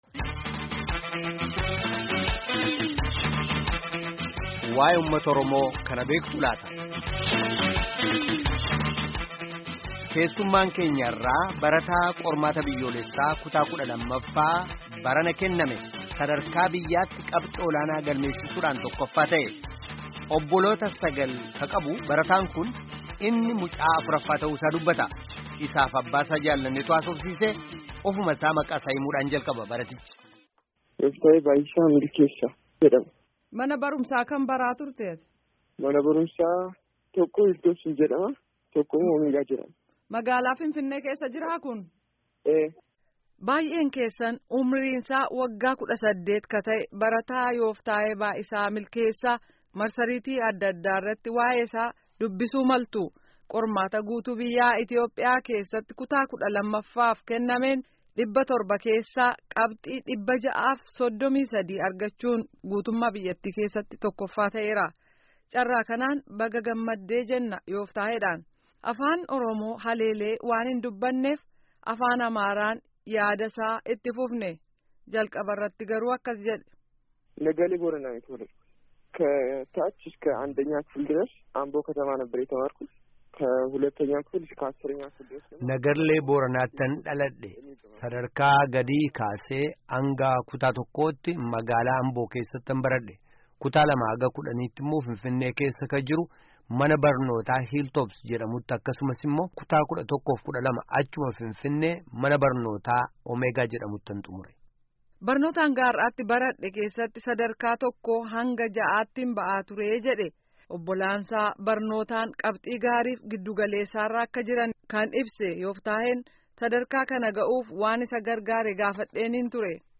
Gaaffii fi deebii caqasaa